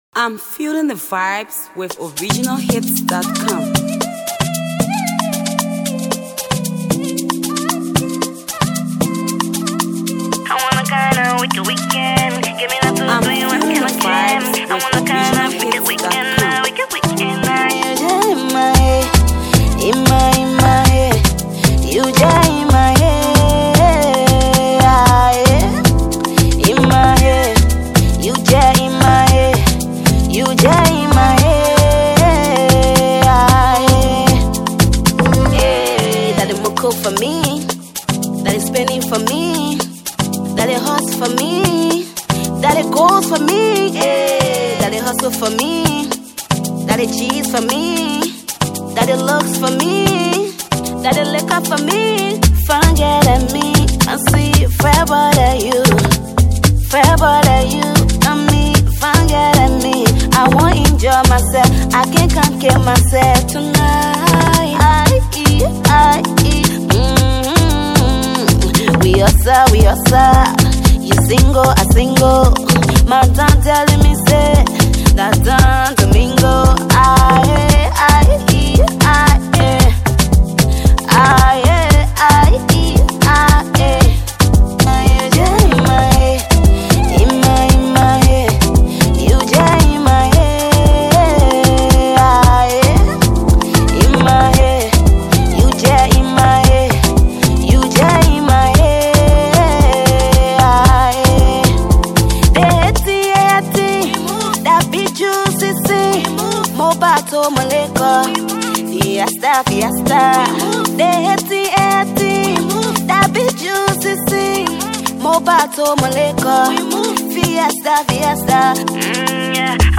Songstress